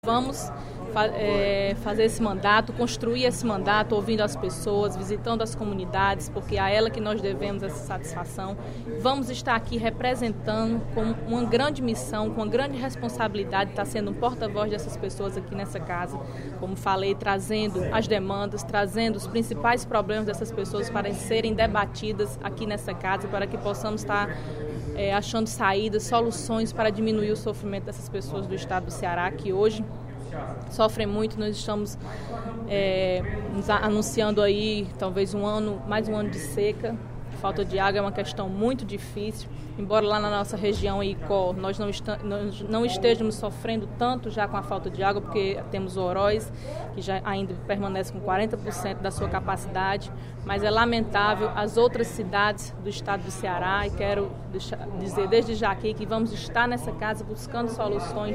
Em pronunciamento na Assembleia Legislativa, durante sessão plenária desta quinta-feira (12/02), a deputada Laís Nunes (Pros) comentou sobre as atividades de seu mandato na Casa.